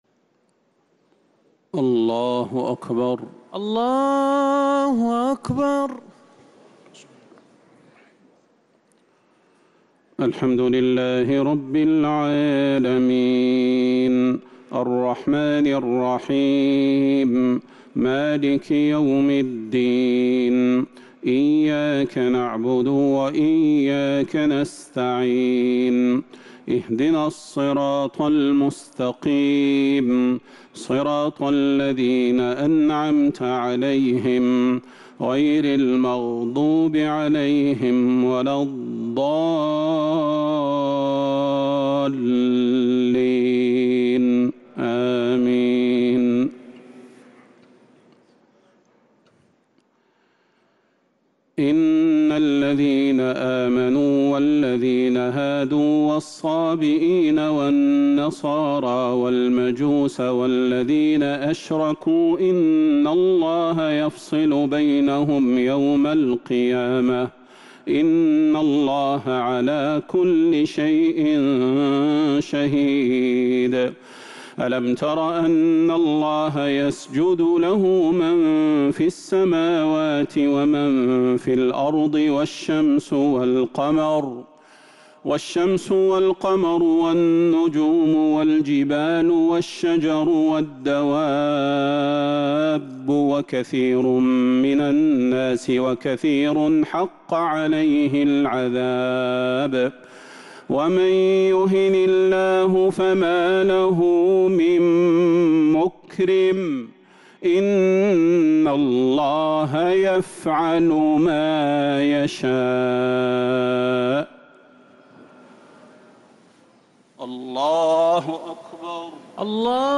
تهجد ليلة 21 رمضان 1447هـ من سورة الحج (17-37) | Tahajjud 21st night Ramadan 1447H Surah Al-Hajj > تراويح الحرم النبوي عام 1447 🕌 > التراويح - تلاوات الحرمين